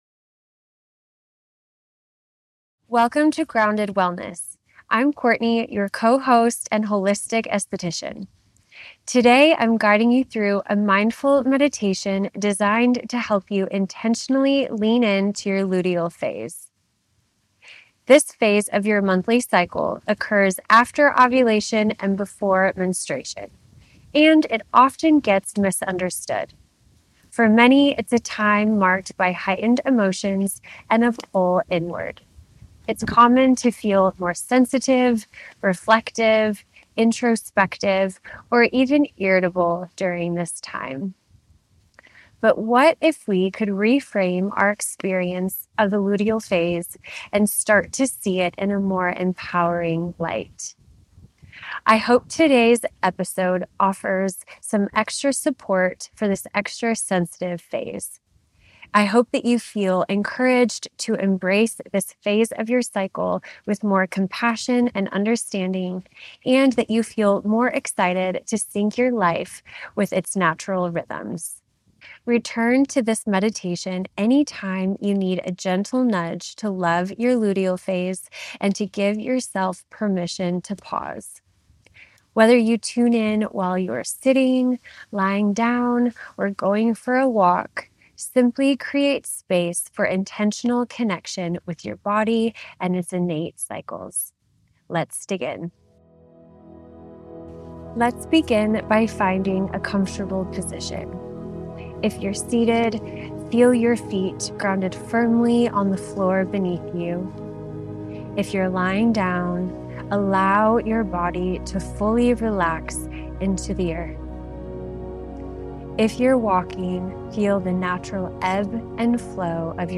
Bonus: Luteal Phase Meditation - Lean In With Intention
Today’s episode is designed to offer extra support in this extra sensitive phase – with gentle affirmations to support emotional well-being and inner reflection, holistic skincare tips to restore balance and practical guidance to sync your lifestyle with your cycle’s natural rhythms.